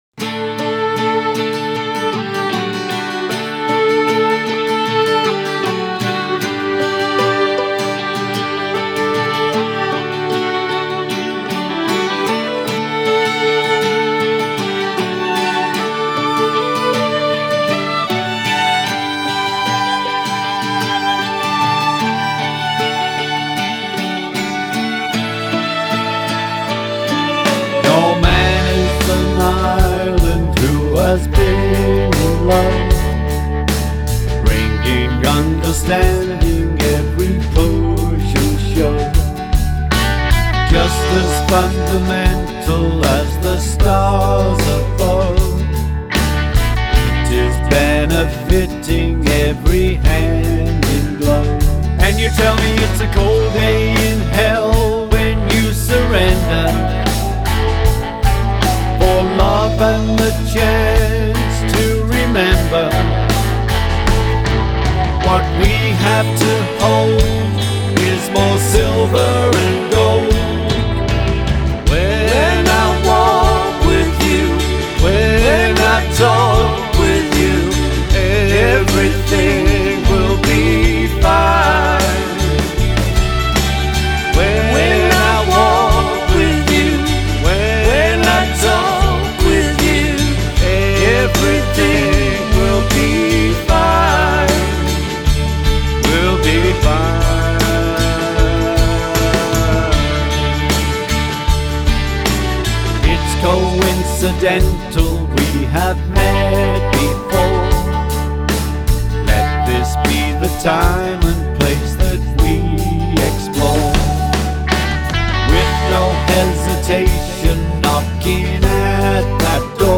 sweet and sentimental